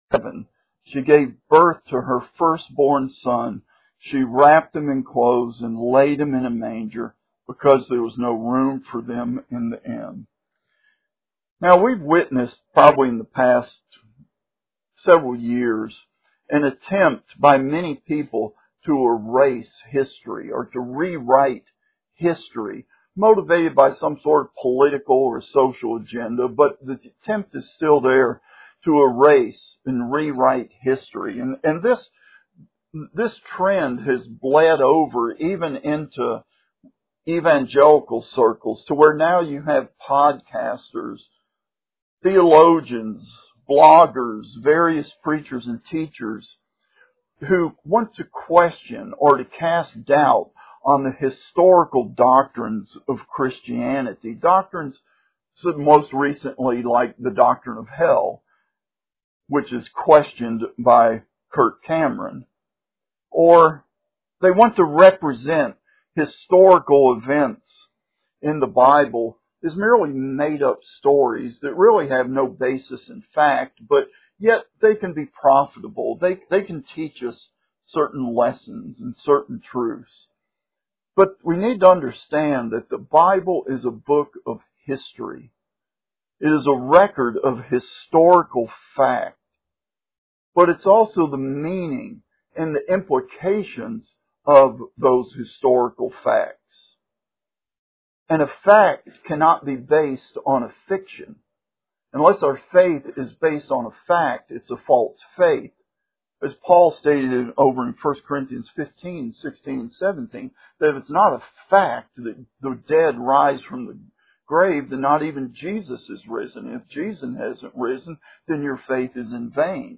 Miscellaneous Sermons